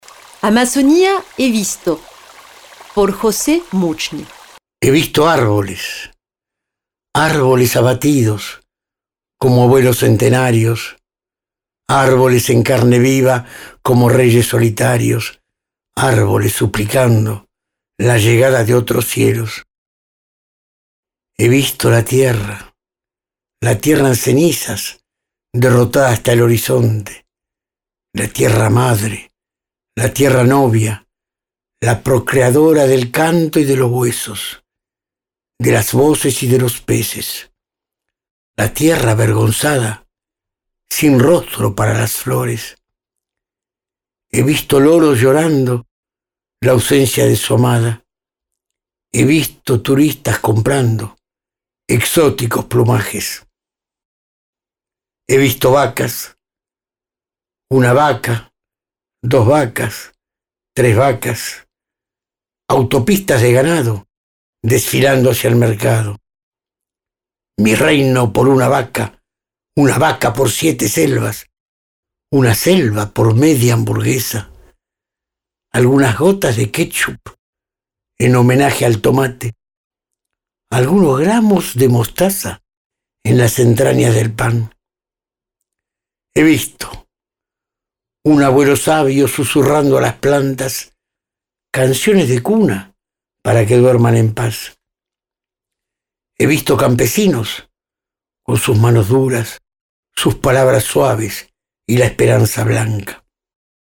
Amazonia he visto leido por el autor